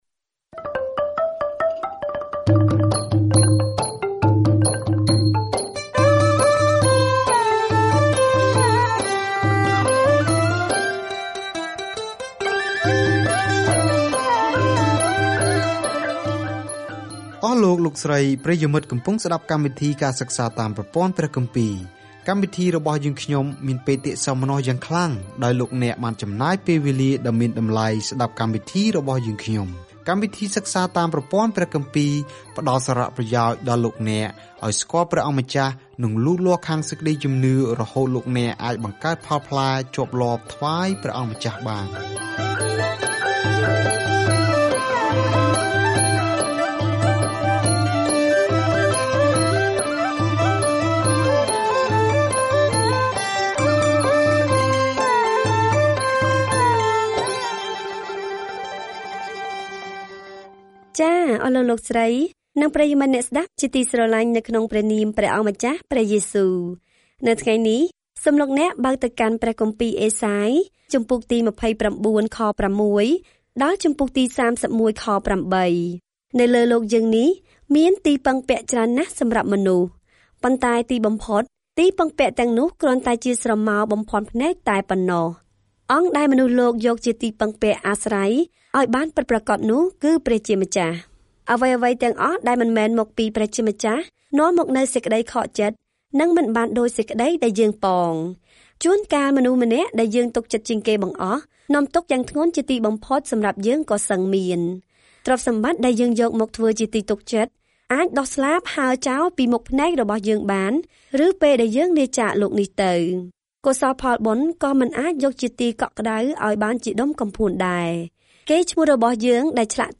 ការធ្វើដំណើរជារៀងរាល់ថ្ងៃតាមរយៈអេសាយ ពេលអ្នកស្តាប់ការសិក្សាជាសំឡេង ហើយអានខគម្ពីរដែលបានជ្រើសរើសពីព្រះបន្ទូលរបស់ព្រះ។